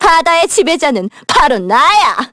Miruru_L-Vox_Skill5_kr.wav